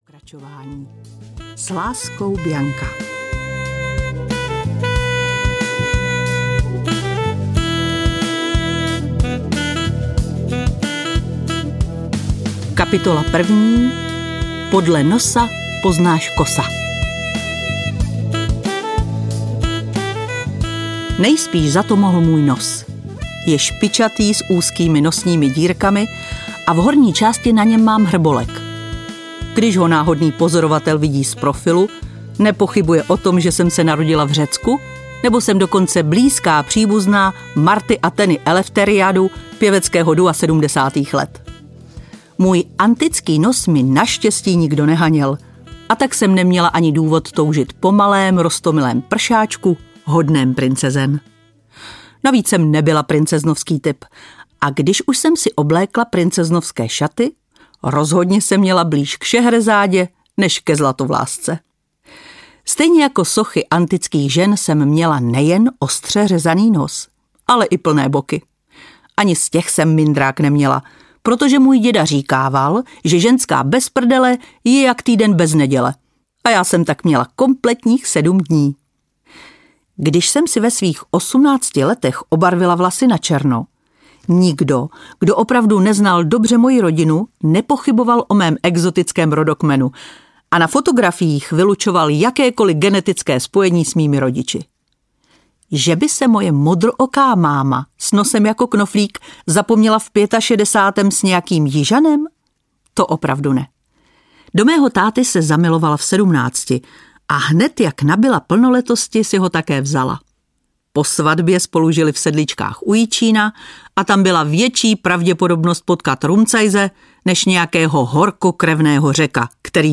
Láska ve špičce italské boty audiokniha
Ukázka z knihy
laska-ve-spicce-italske-boty-audiokniha